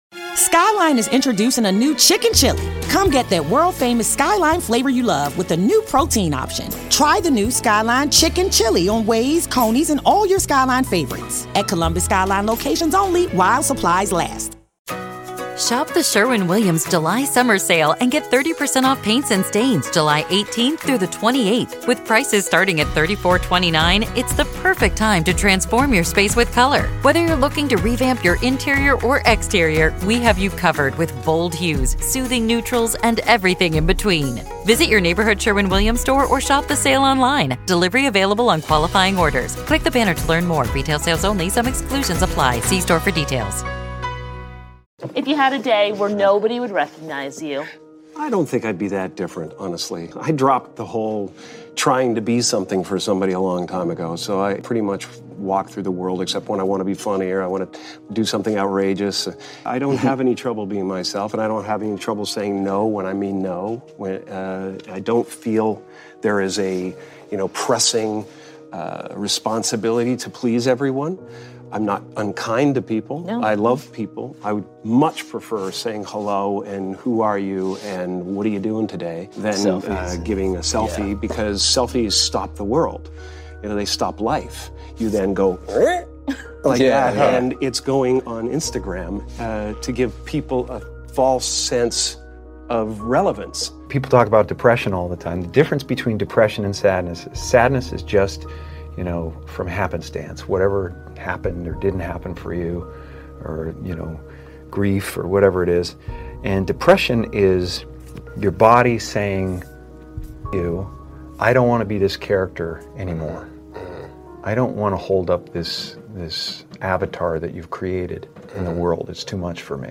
Jim Carrey's Speech NO ONE Wants To Hear - One Of The Most Eye- Opening Speeches